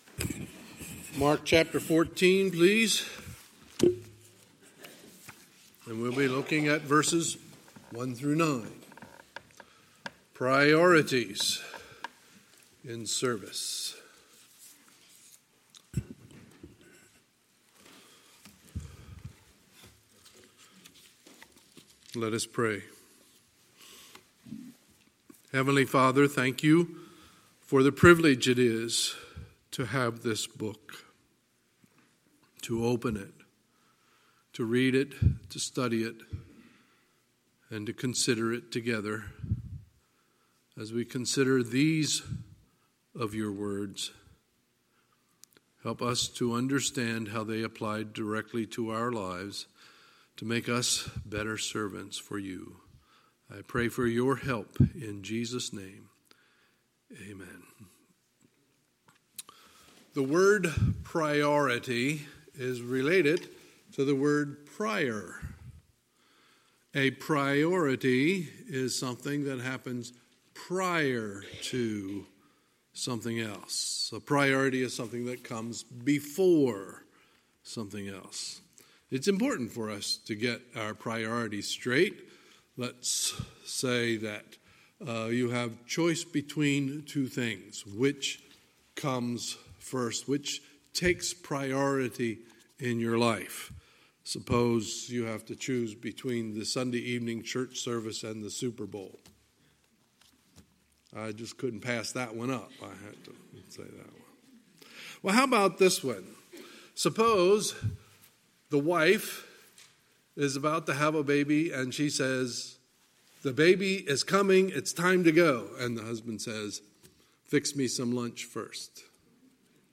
Sunday, October 20, 2019 – Sunday Morning Service